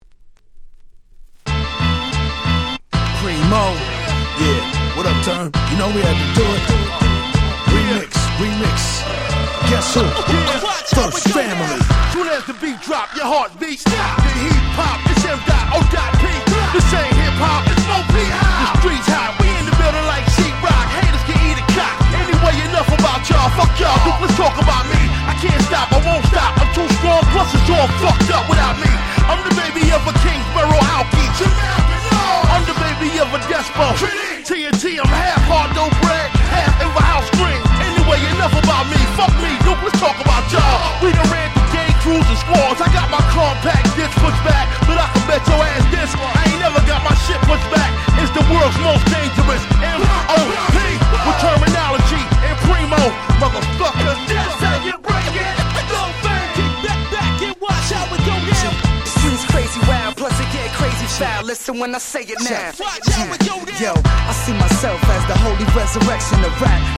06' Very Nice Hip Hop / Boom Bap !!
Underground Hip Hop